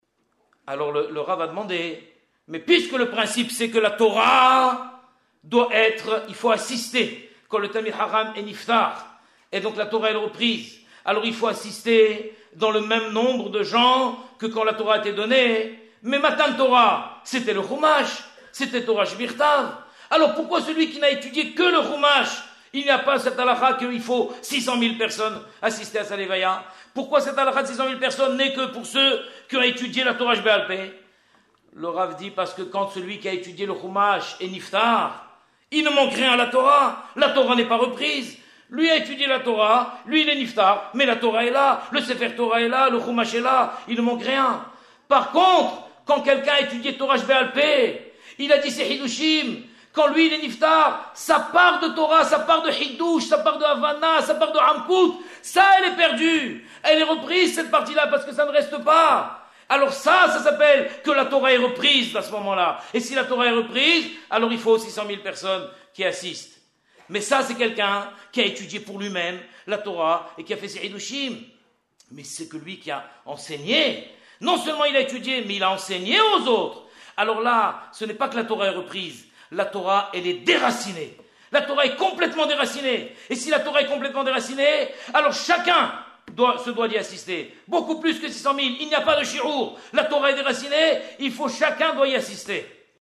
01:53:15 Nous nous trouvons dans le grand Beith Hamidrash Nishmath Israël des Institutions Yad Mordekhaï à Paris, le 11 novembre 2001, soit 9 jours après le rappel à la Yeshiva Shel Maala du Gadol Hador MORENOU VERABENOU HAGAON HARAV ELAZAR MENA’HEM MAN SHACH ZATSAL.